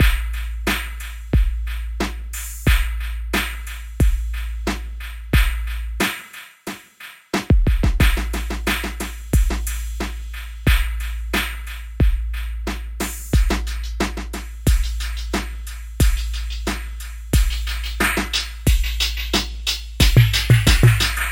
Drum Loop Hurt Without Crash
描述：对于蓝调
标签： 90 bpm Blues Loops Drum Loops 3.59 MB wav Key : Unknown
声道立体声